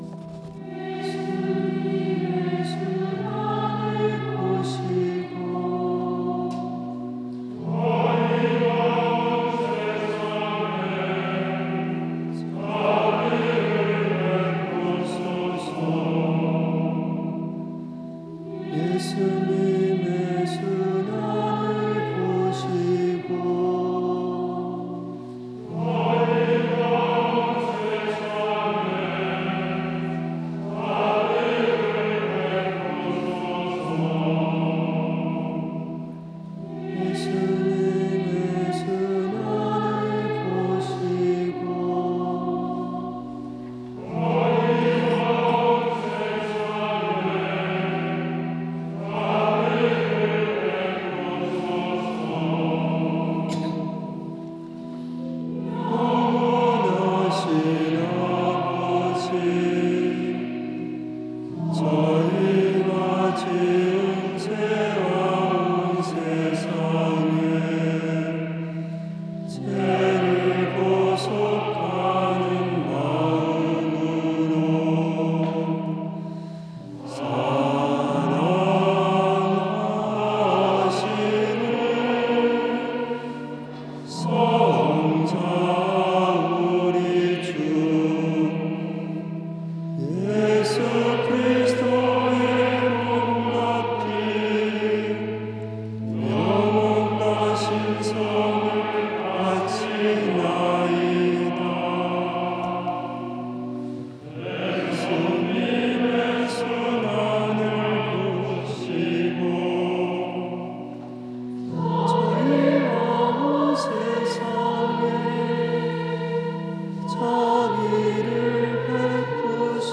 하느님 자비의 신심미사와 자비의 5단 기도 노래로 봉헌